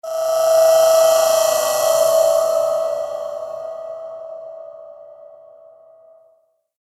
Royalty free sounds: Creatures